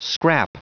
Prononciation du mot scrap en anglais (fichier audio)
Prononciation du mot : scrap